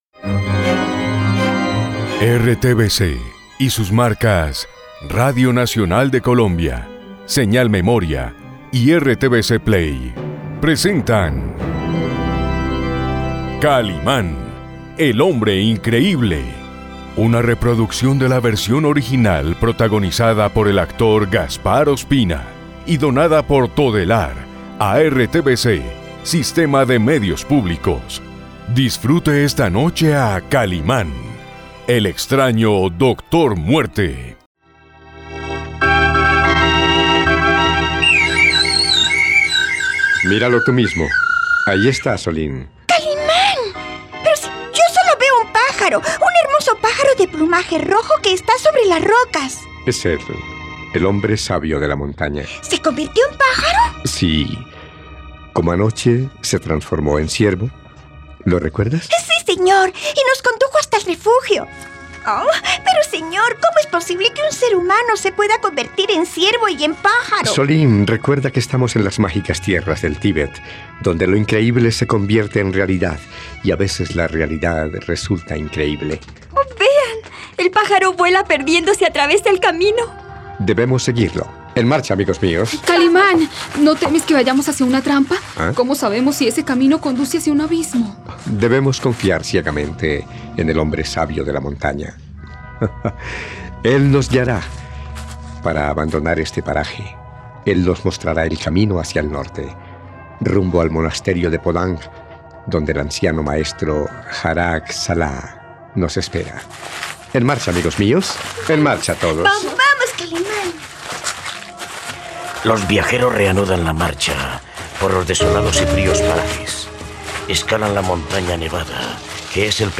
..Radionovela. El monje reaparece para advertir al hombre increíble sobre una inminente traición.